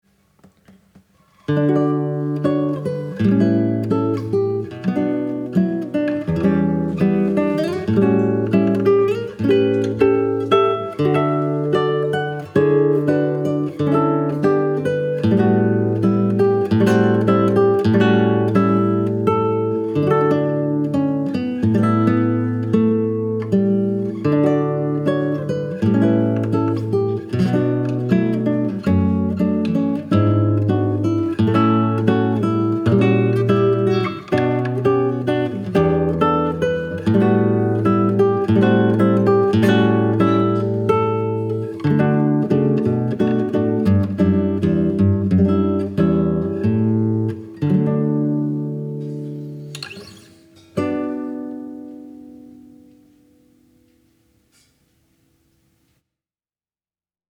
Stop. Ich schreibe, die Aufnahme klingt charakterlos. Damit meine ich, dass bei der Aufnahme gegenüber dem Original etwas verloren gegangen ist.
Es ist eine Interpretationsfrage und die Aufnahme lässt beides zu.